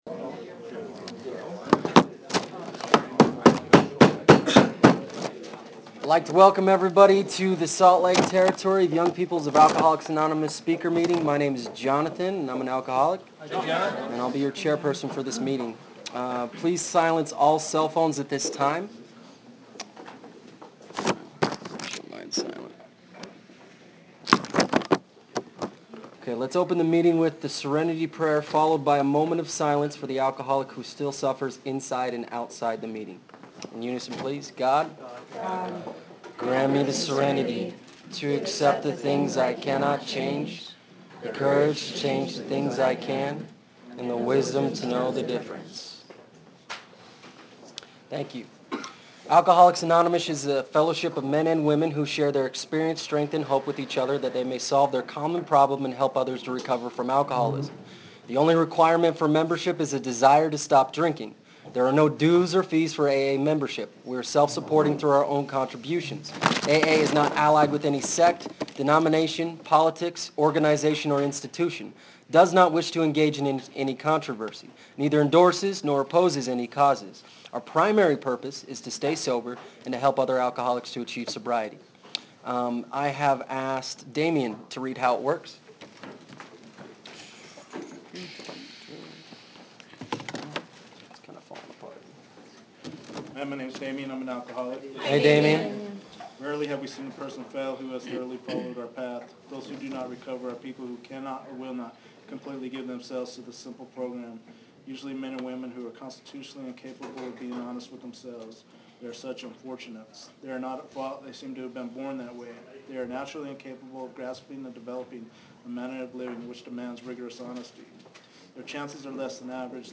Monte Carlo Night Speaker Tape